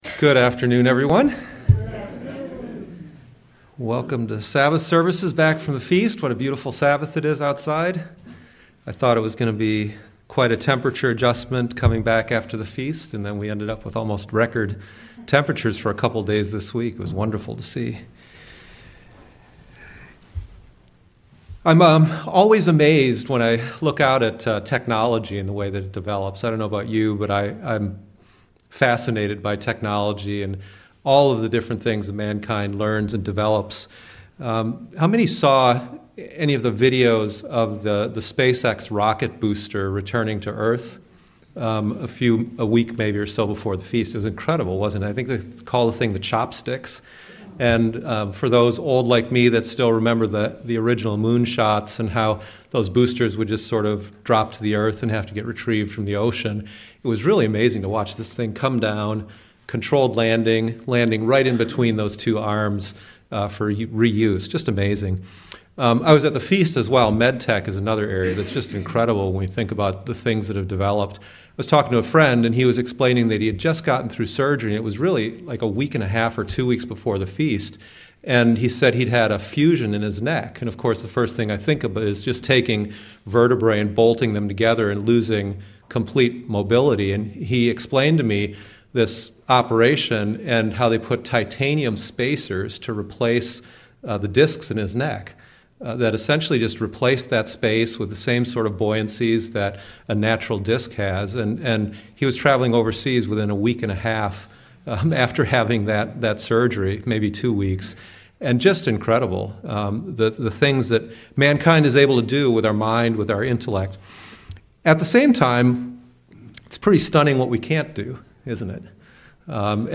Sermons
Given in Cleveland, OH